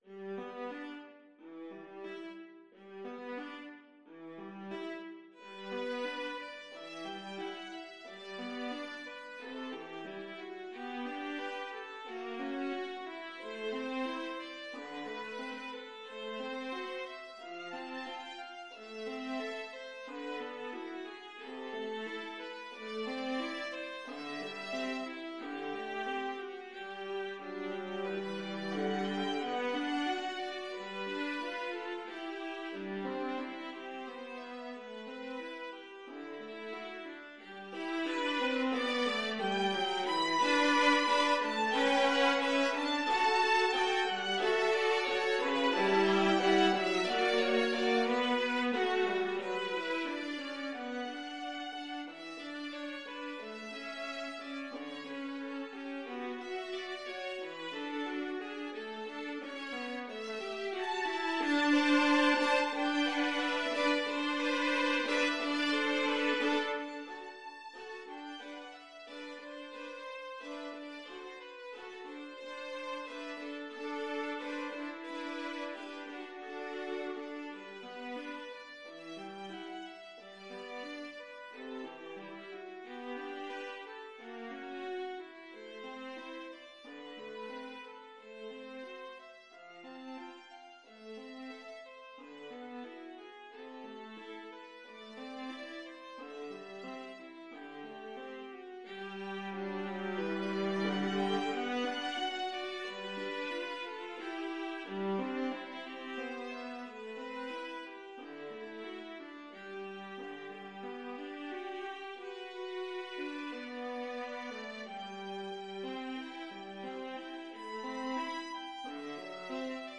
Free Sheet music for Viola Duet
2/4 (View more 2/4 Music)
~ = 100 Allegretto con moto =90
G major (Sounding Pitch) (View more G major Music for Viola Duet )
Classical (View more Classical Viola Duet Music)